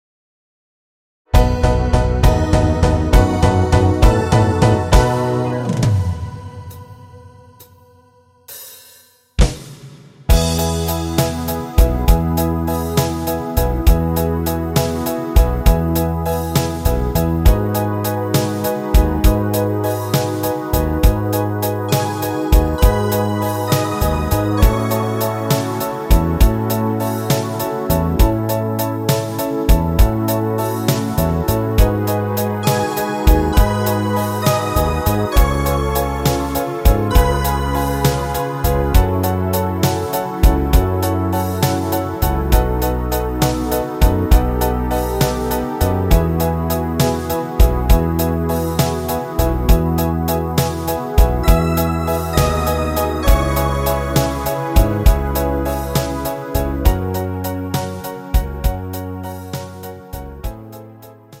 Rhythmus  Slowrock
Art  Italienisch, Schlager 70er